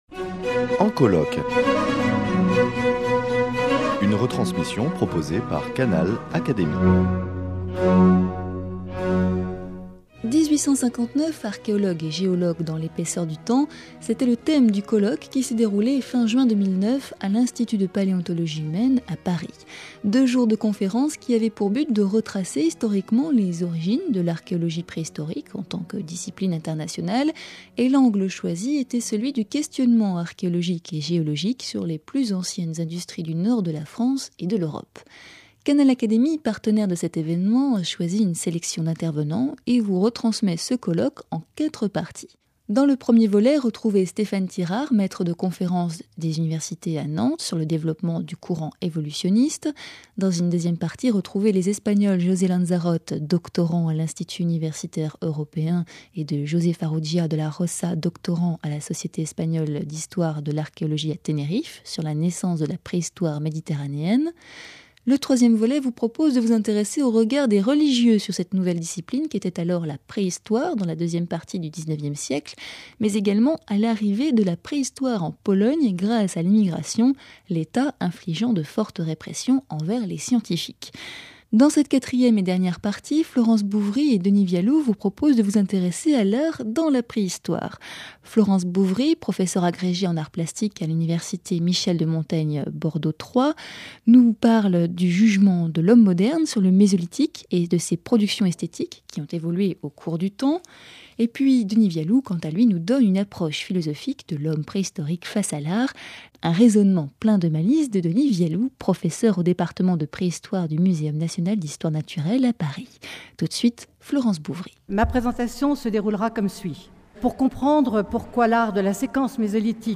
Canal Académie retransmet ce colloque en quatre parties.